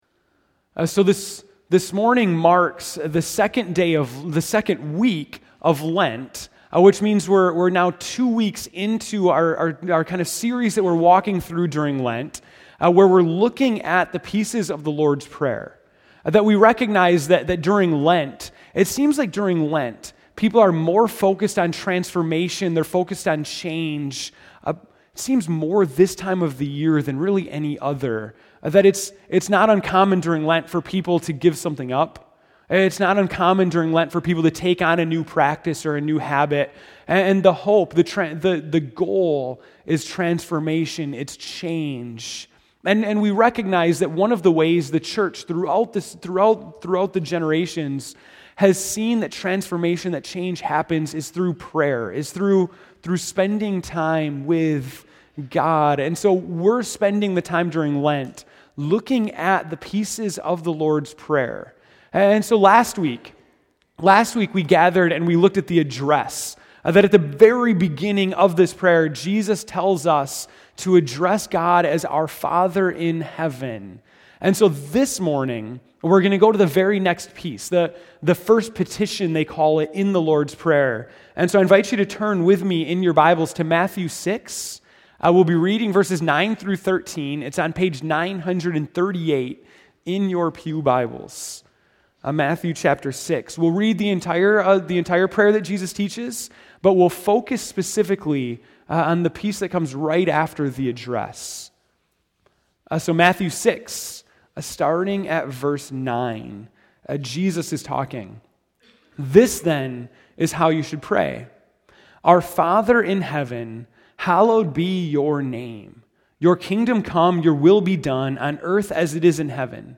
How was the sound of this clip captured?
March 1, 2015 (Morning Worship)